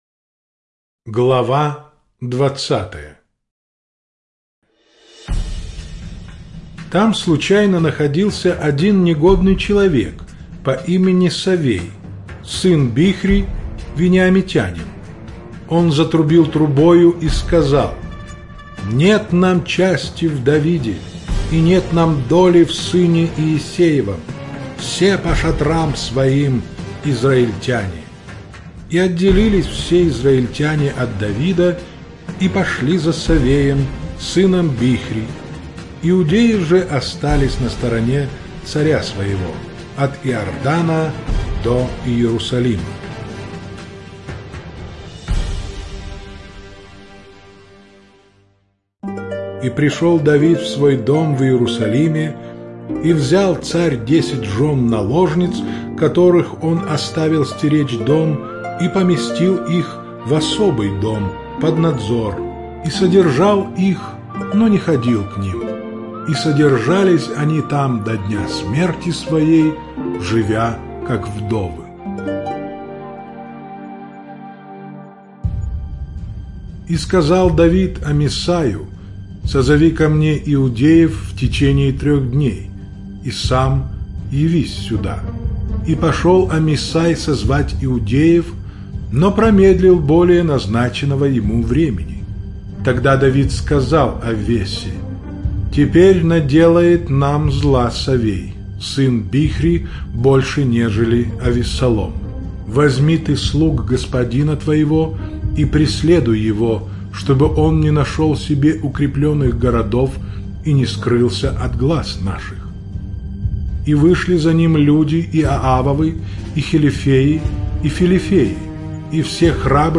Жанр: Аудиокнига
Чтение сопровождается оригинальной музыкой и стерео-эффектами.